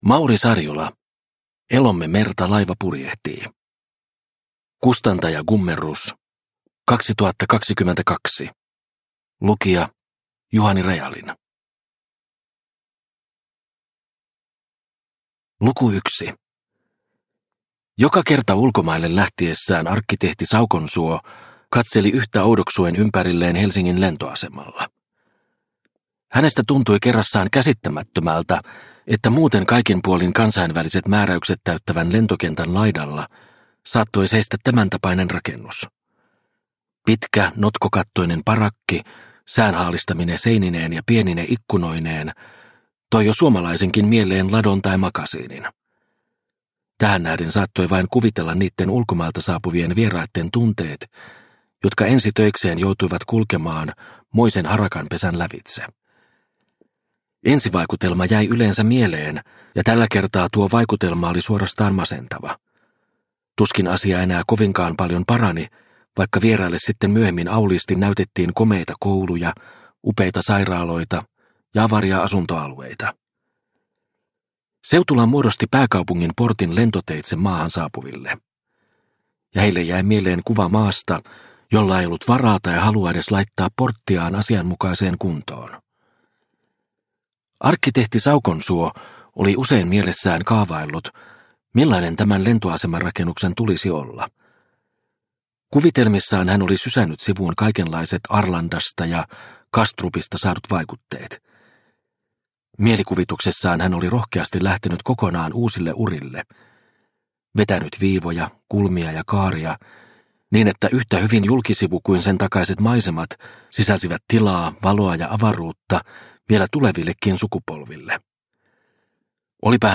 Elomme merta laiva purjehtii – Ljudbok – Laddas ner